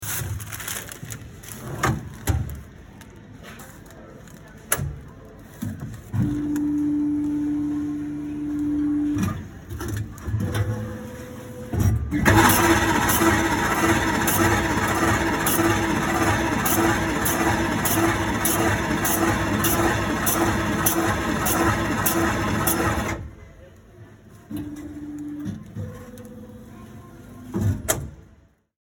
Звуки хлебопечки
На этой странице собраны звуки хлебопечки — от мерного гула двигателя до сигнала готовности хлеба.